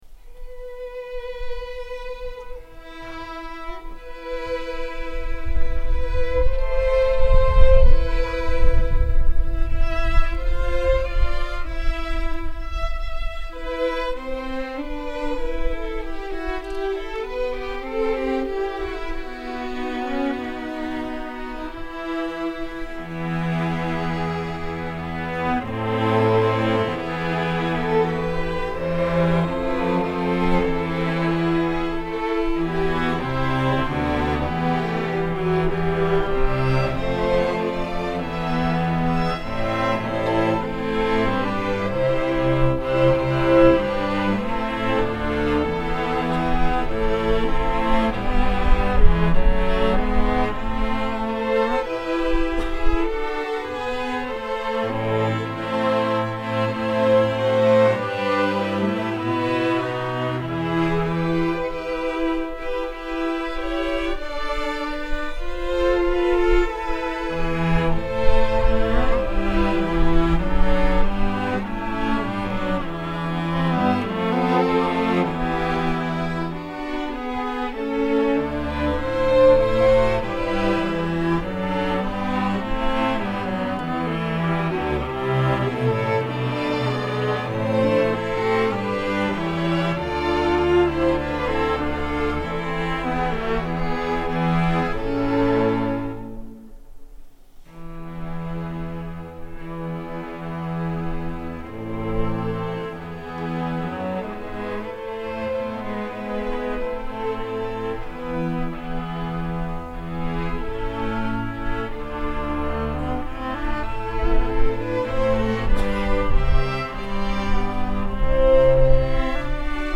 “O Magnum Mysterium” για Ορχήστρα Εγχόρδων (live)